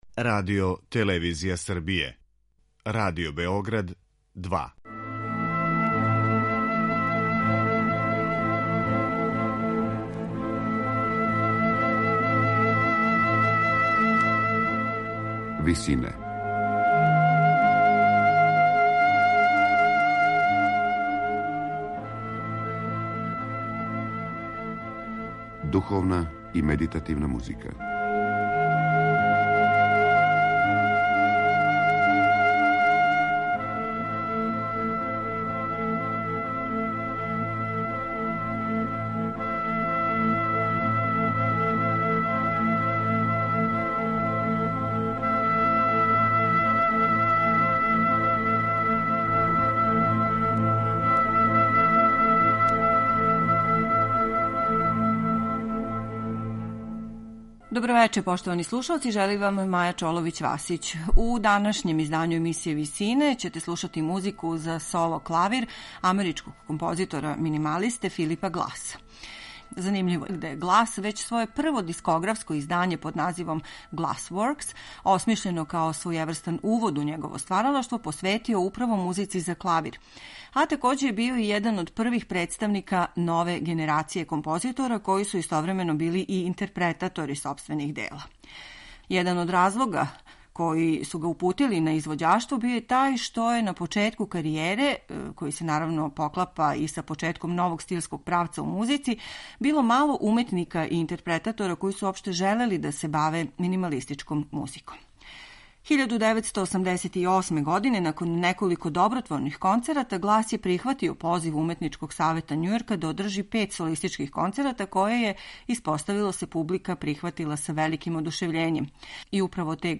минималиста